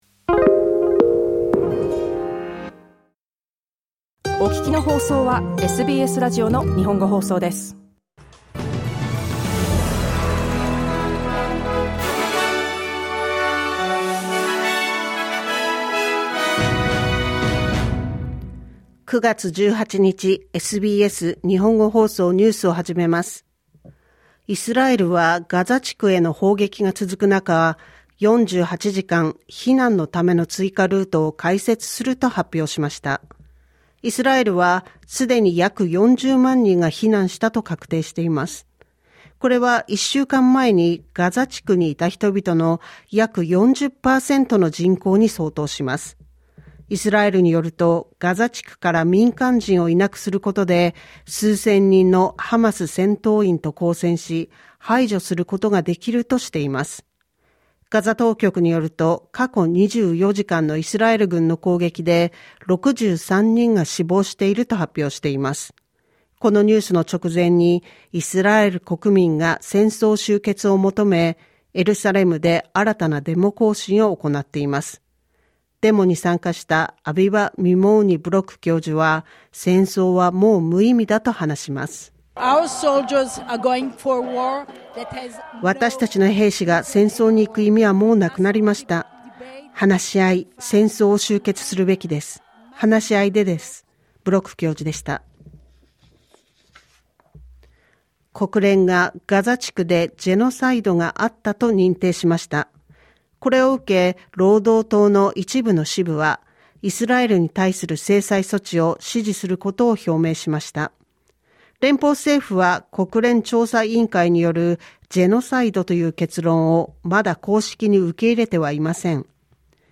News from today's live program (1-2pm).